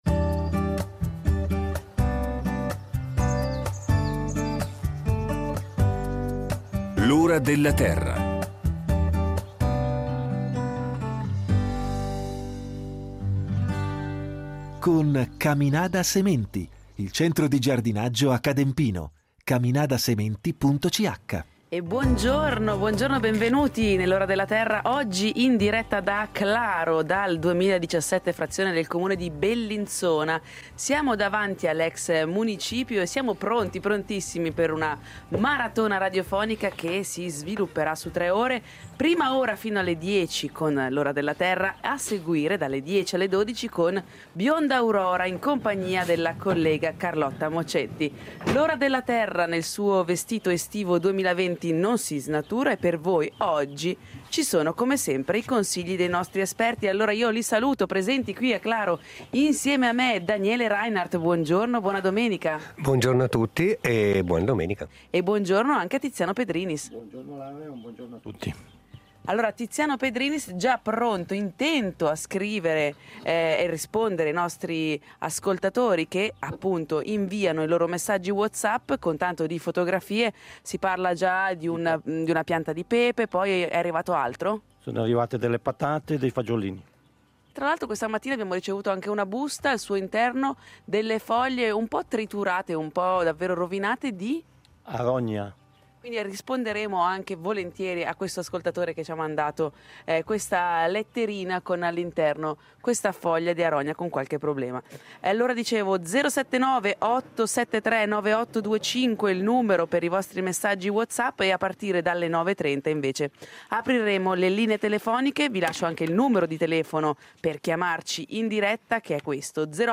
L’estate 2020 vede L’Ora della Terra Itinerante sul territorio, in compagnia del programma Bionda Aurora.